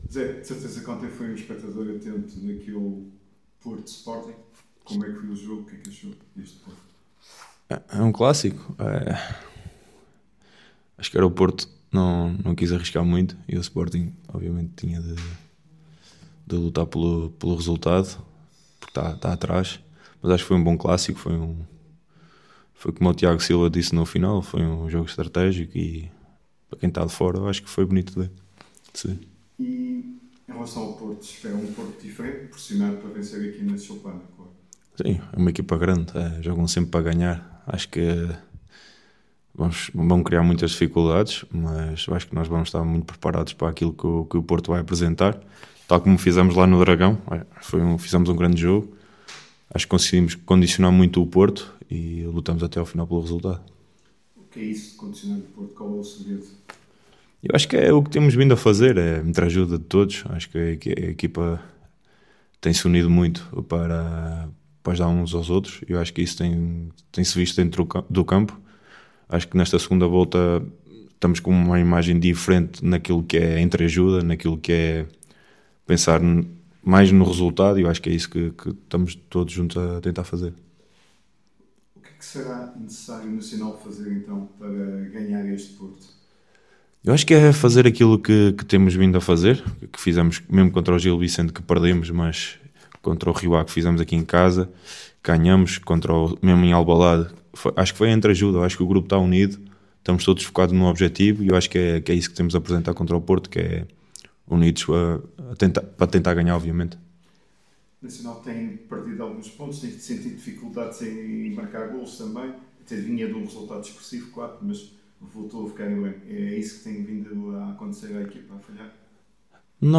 esteve presente na primeira conferência de imprensa semanal.